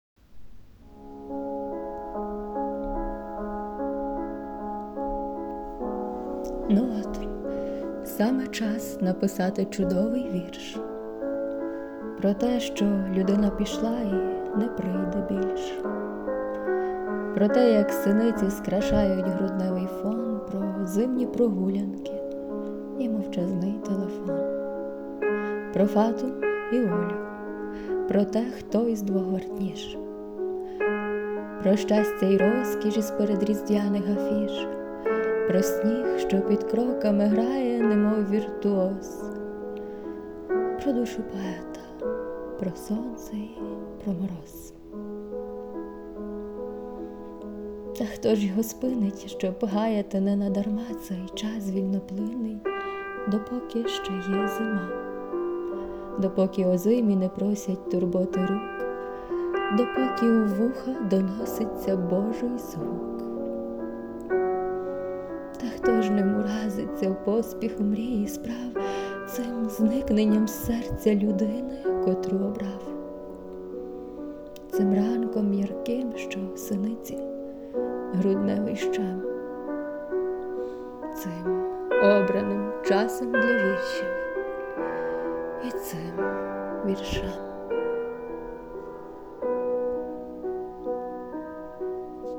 Фон – Л.Бетховен. Соната 14
ВИД ТВОРУ: Вірш